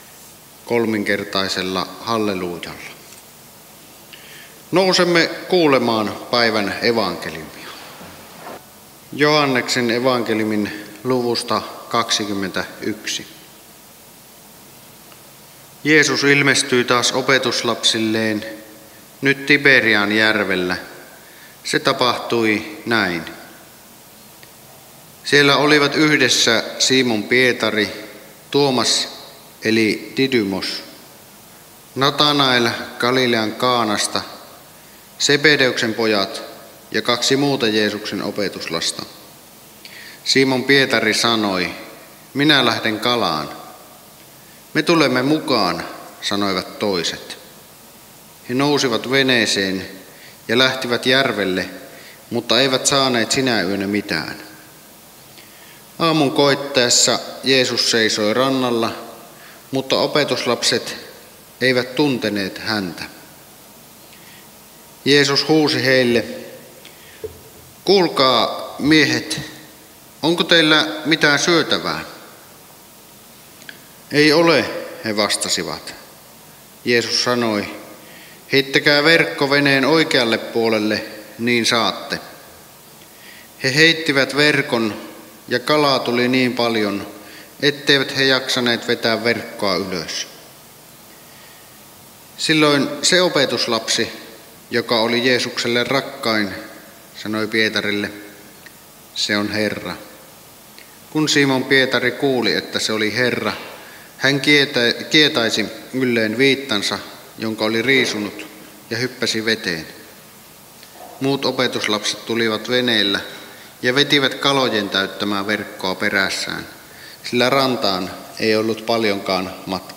saarna Kälviällä 1. sunnuntaina pääsiäisestä Tekstinä Joh. 21: 1-14